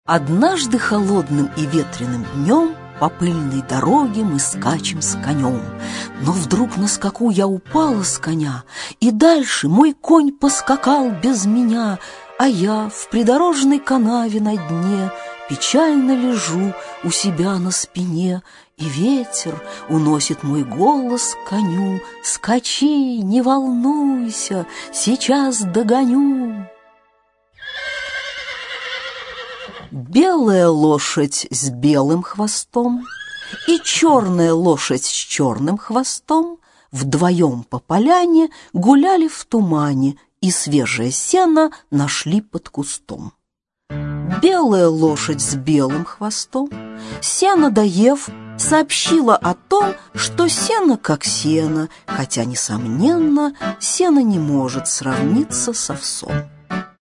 Автор Рената Муха Читает аудиокнигу Дина Рубина.